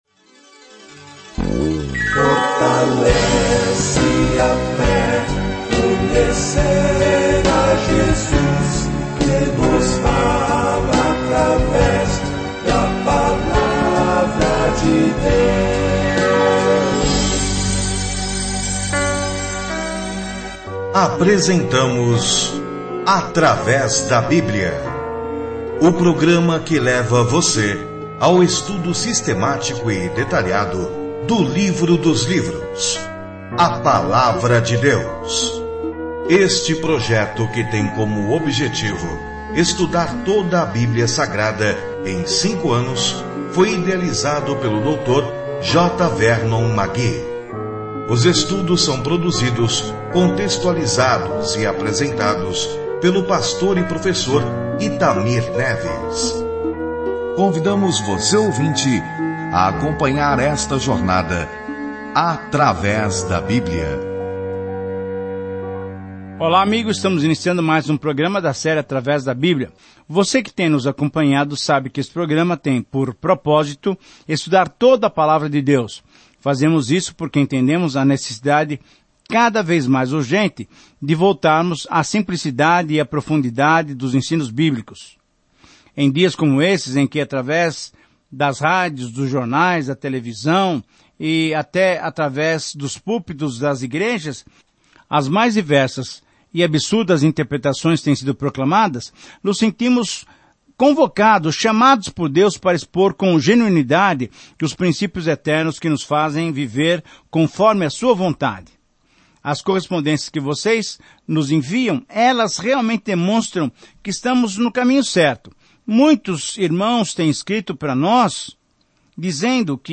As Escrituras Ester 7:1-10 Dia 7 Começar esse Plano Dia 9 Sobre este Plano Deus sempre cuidou do seu povo, mesmo quando conspirações genocidas ameaçam a sua extinção; uma história incrível de como uma garota judia enfrenta a pessoa mais poderosa do mundo para pedir ajuda. Viaje diariamente por Ester enquanto ouve o estudo em áudio e lê versículos selecionados da palavra de Deus.